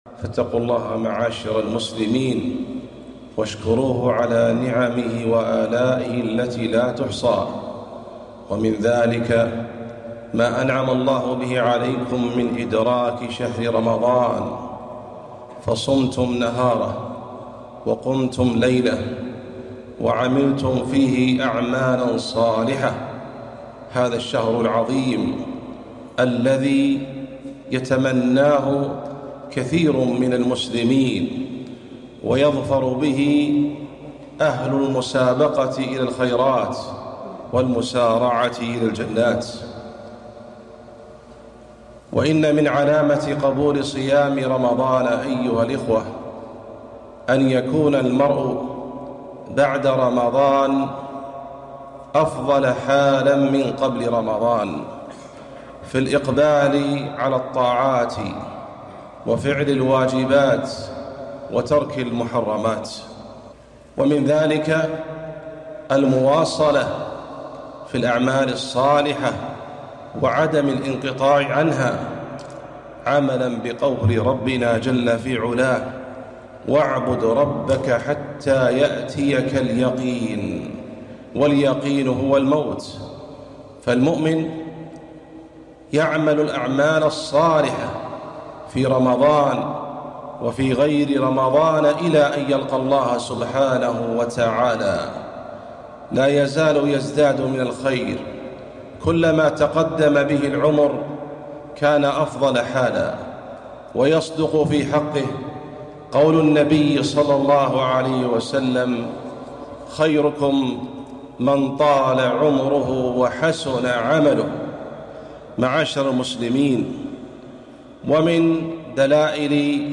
خطبة - مسائل في صيام ست من شوال